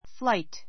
fli gh t 小 A2 fláit ふ ら イ ト （ ⦣ gh は発音しない） 名詞 ❶ 飛ぶこと , 飛行; 飛行機の旅 , 飛行便 Have a nice flight.